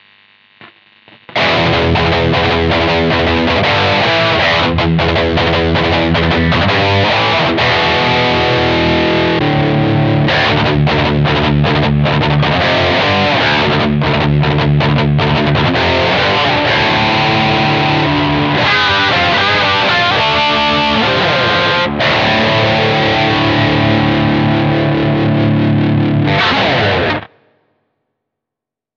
TUNE DOWN
レイテンシー（音の遅延）はというと、残念ながら感じます。
また、トレモロのように周期的に音量が変化するように聞こえます。
エフェクトOFFから弾き始め途中でONにしています。
１音下げ（設定　−２）